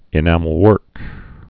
(ĭ-năməl-wûrk)